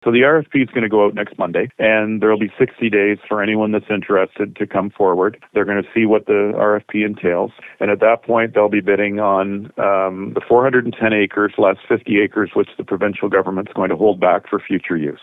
Central Elgin Mayor Andrew Sloan outlines the request for proposal (RFP) process that will launch on Monday (March 2).